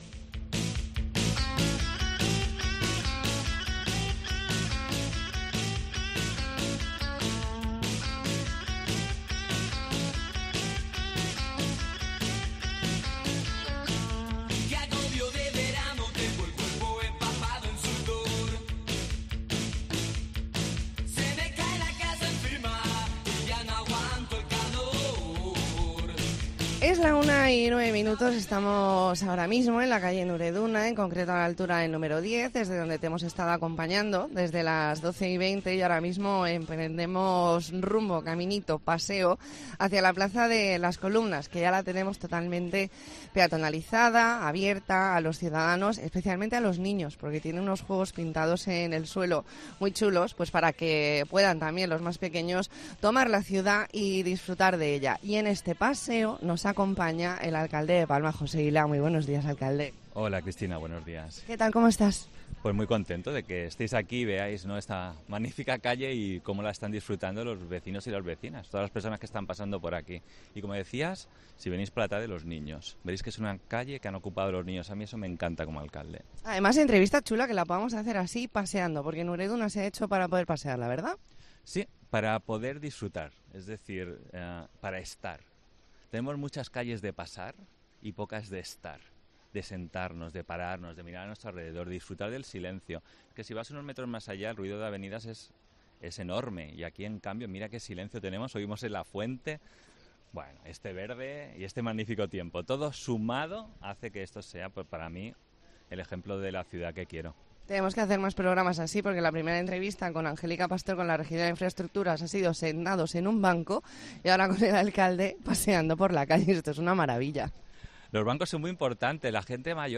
Redacción digital Madrid - Publicado el 28 oct 2022, 13:10 - Actualizado 18 mar 2023, 23:18 1 min lectura Descargar Facebook Twitter Whatsapp Telegram Enviar por email Copiar enlace Hablamos con José Hila, alcalde de Palma. Entrevista en La Mañana en COPE Más Mallorca, viernes 28 de octubre de 2022.